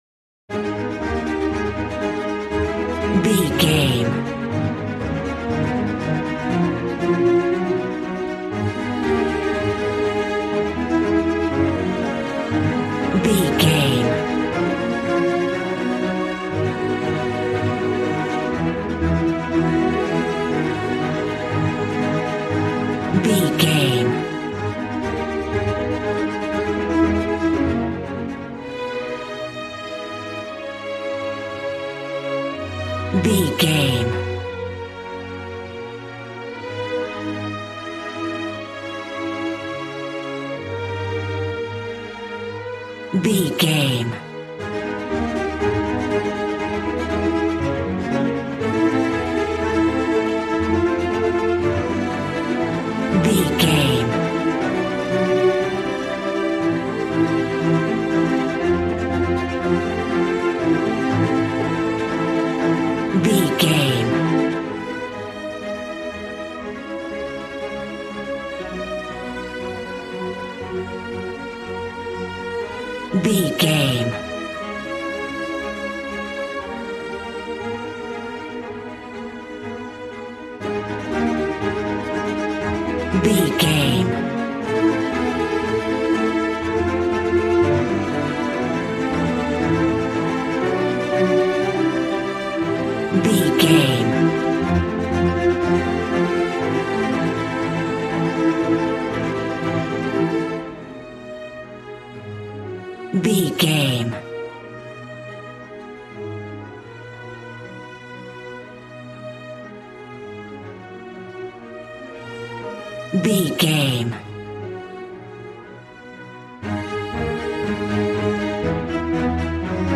Regal and romantic, a classy piece of classical music.
Aeolian/Minor
A♭
Fast
regal
strings
brass